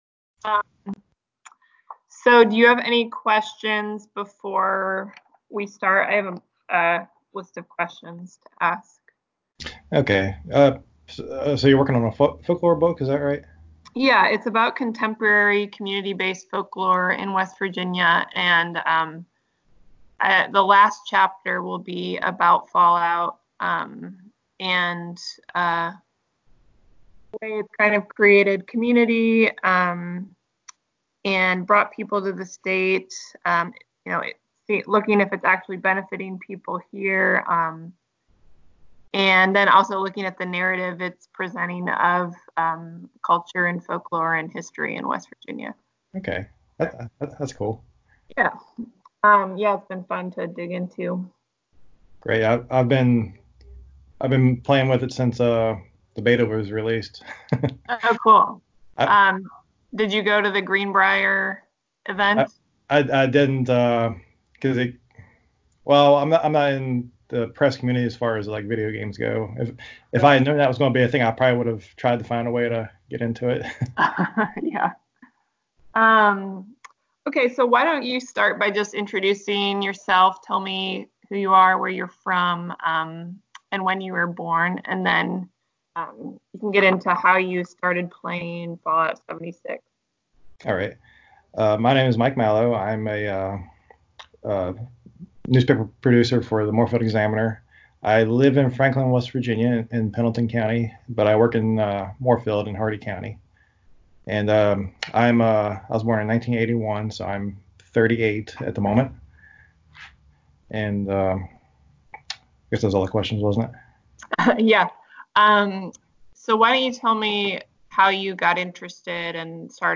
This interview is part of a series of interviews conducted with Fallout 76 gamers, some of whom are from West Virginia, and some of whom were inspired to visit West Virginia because of the game.